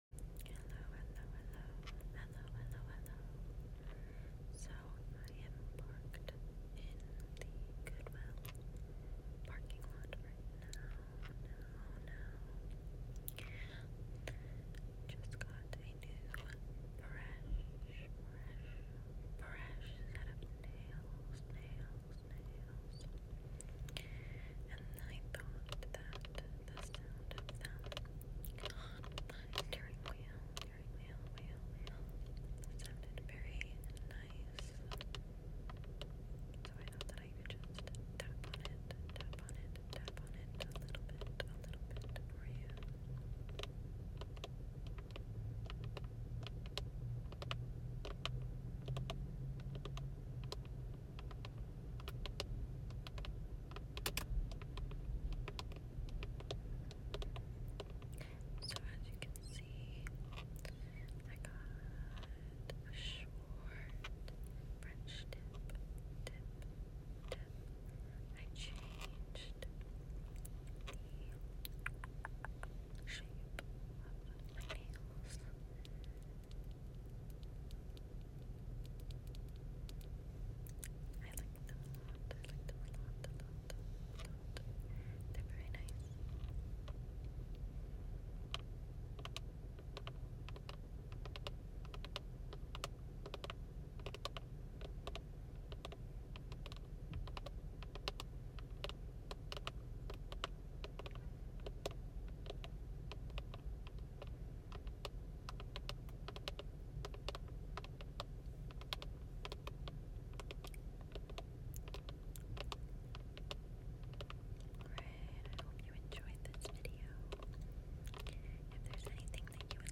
ASMR tapping with my new sound effects free download
ASMR tapping with my new nails!